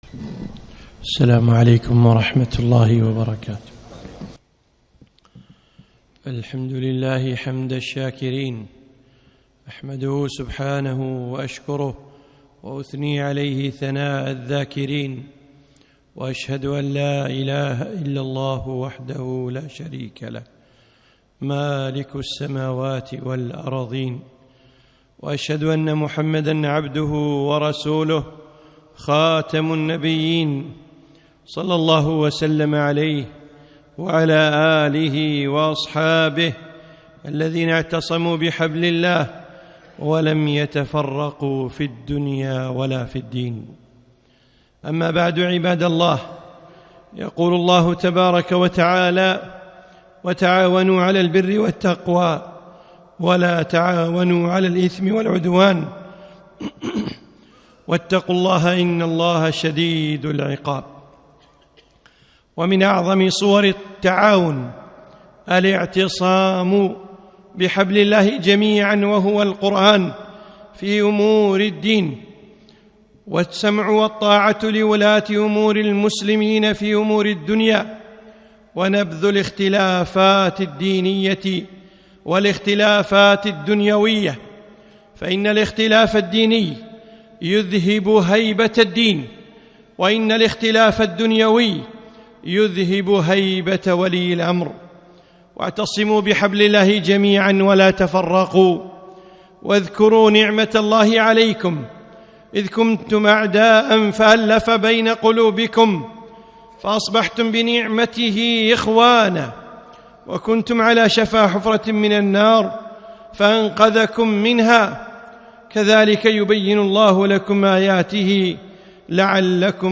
خطبة - أطيعوا أمرائكم